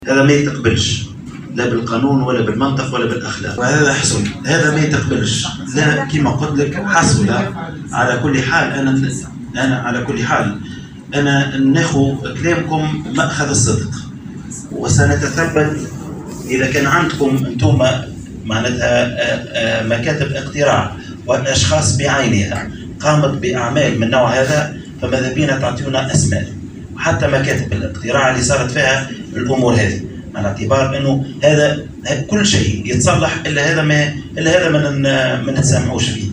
وعن هذه الحوادث قال نائب رئيس هيئة الانتخابات ماهر الجديدي، في تصريح للجوهرة أف أم، إن الهيئة ستتفاعل مع ملاحظات المنظمة التونسية للدفاع عن حقوق الأشخاص ذوي الإعاقة، وستتثبت من هذه الحوادث غير المقبولة والتي لا يمكن التسامح معها، وفق تعبيره. ودعا الجديدي المنظمة إلى مد هيئة الانتخابات إلى مدها بمزيد من التفاصيل بخصوص هويات المتنمرين ومكاتب الاقتراع التي حصلت بها هذه التجاوزات.